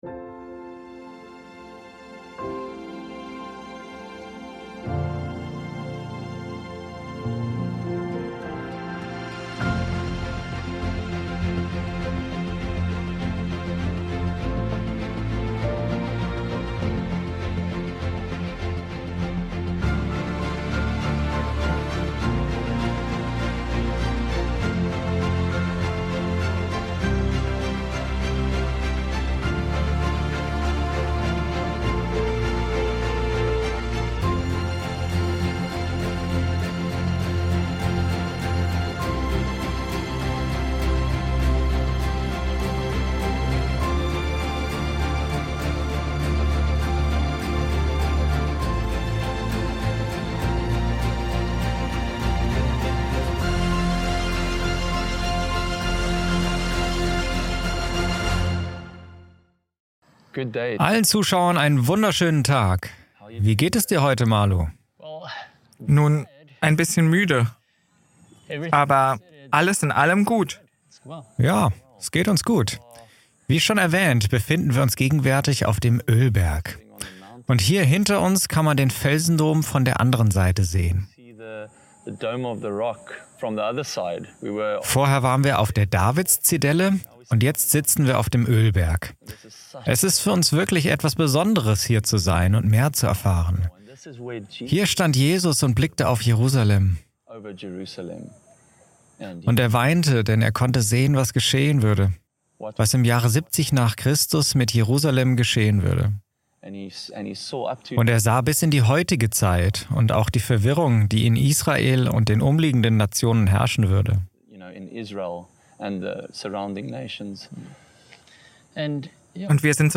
In diesem fesselnden Vortrag werden zentrale Fragen zur prophetischen Bedeutung des Antichristen und des Tempels Gottes behandelt. Es wird diskutiert, dass der Antichrist bereits vorhanden ist und welche Rolle die Kirche als geistliches Israel spielt.